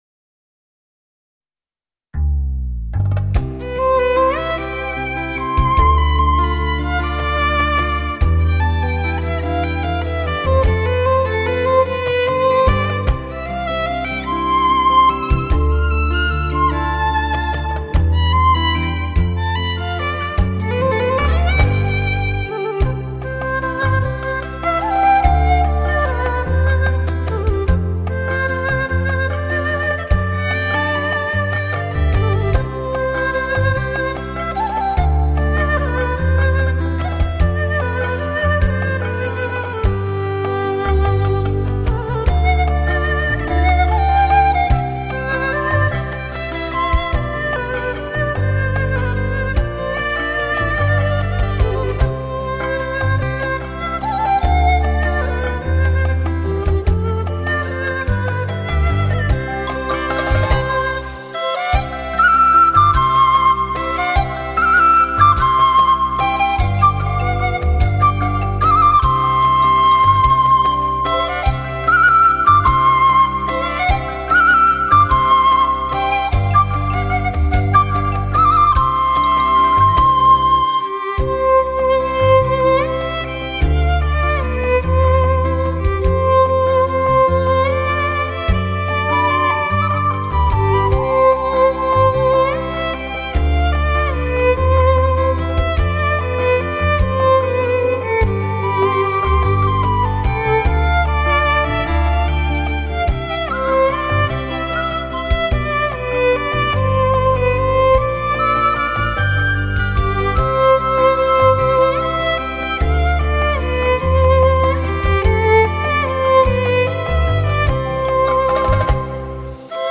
[分享]笛曲：《黄昏放牛》